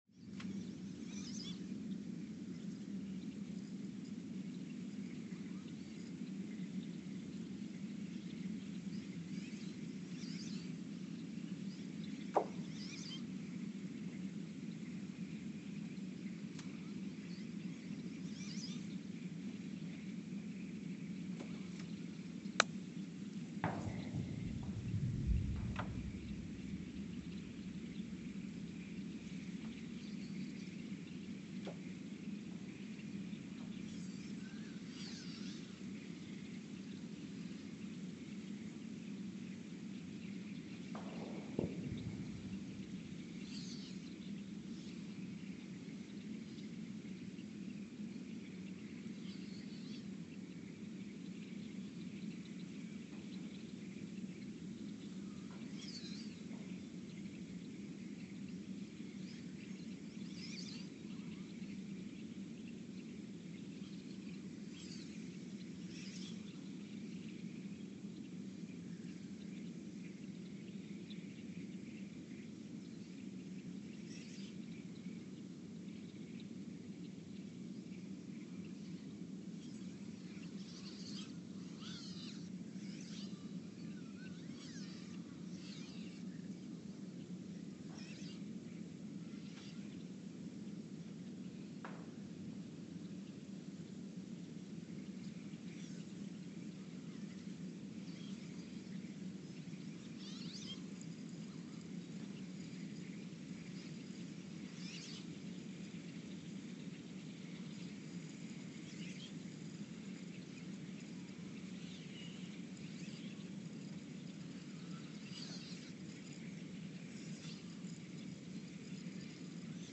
The Earthsound Project is an ongoing audio and conceptual experiment to bring the deep seismic and atmospheric sounds of the planet into conscious awareness.
Speedup : ×900 (transposed up about 10 octaves)
Loop duration (audio) : 11:12 (stereo)